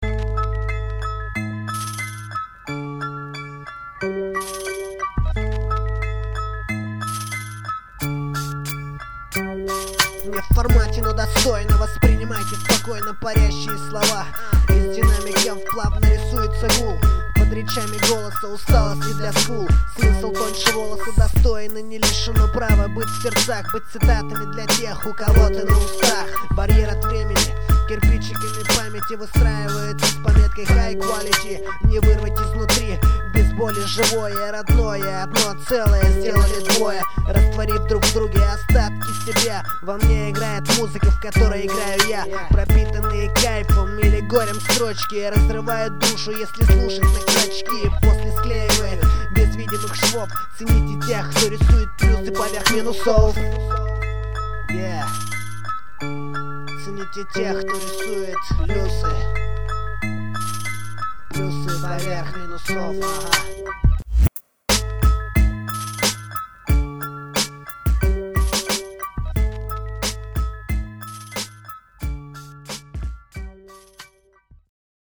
2006 Рэп
Треки на различные баттлы...